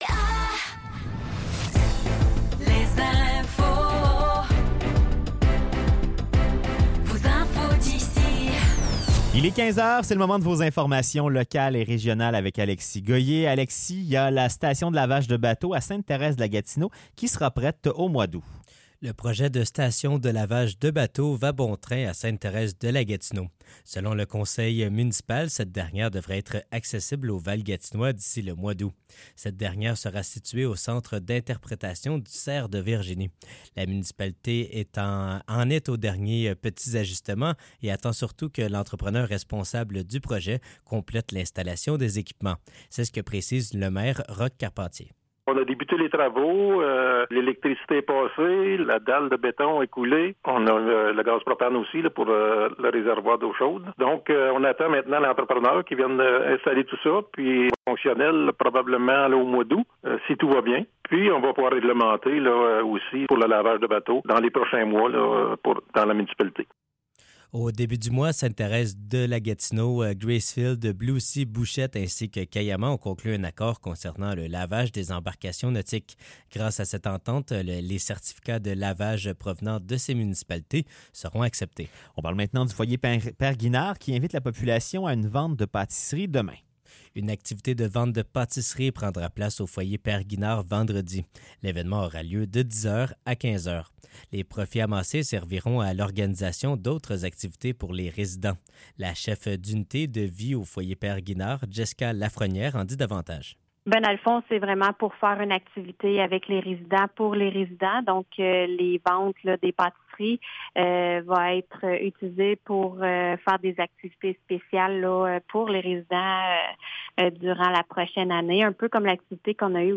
Nouvelles locales - 25 juillet 2024 - 15 h